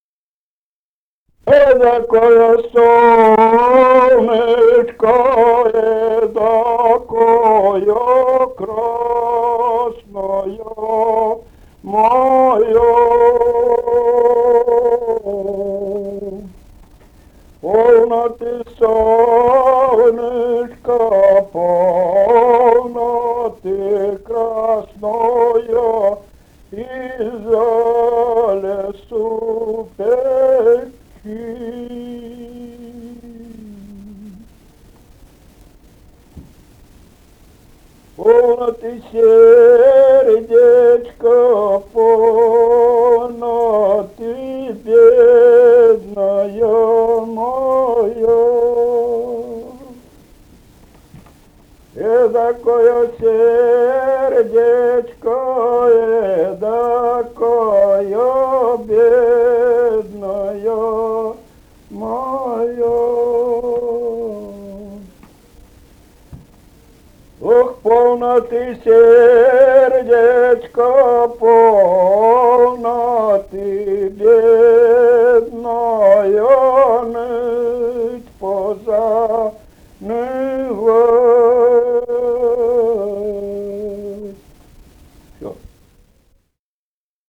Этномузыкологические исследования и полевые материалы
«Эдакоё солнышко» (лирическая).